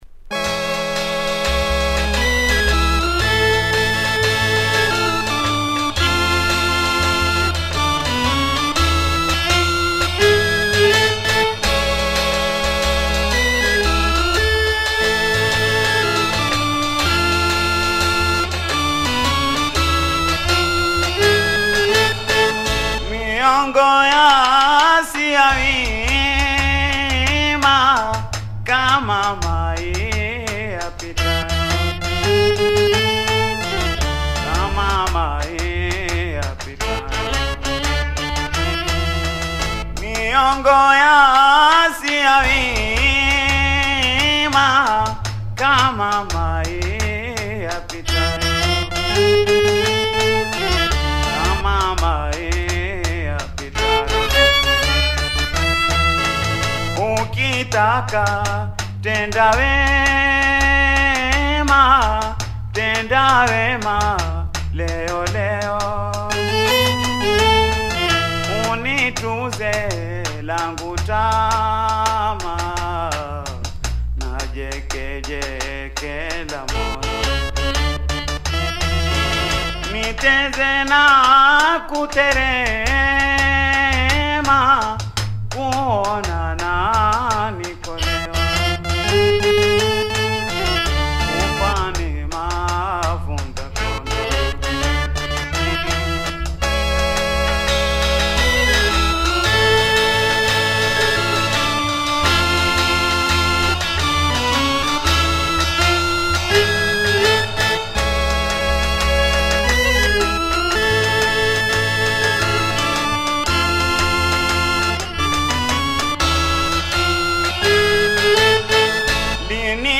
Raw taarab